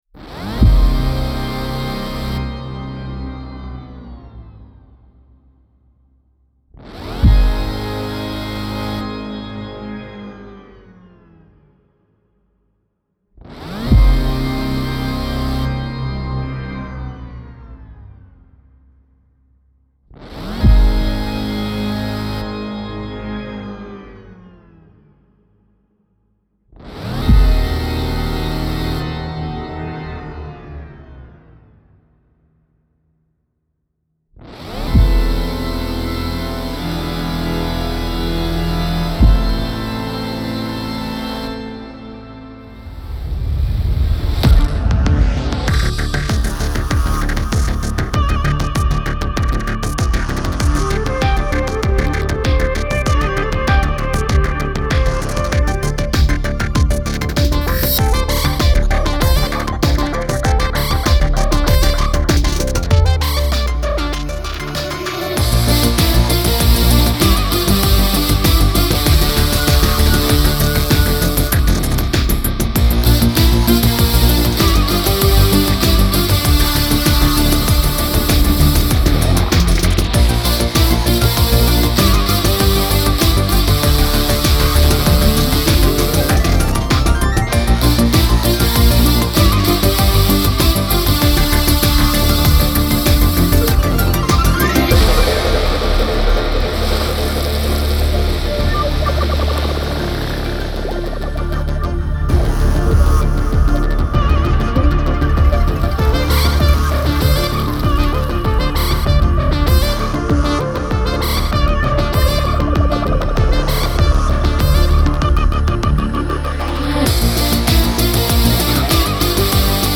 Genre : Électronique, Techno